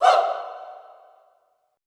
Index of /90_sSampleCDs/Best Service - Extended Classical Choir/Partition I/FEM SHOUTS
FEM HOO   -L.wav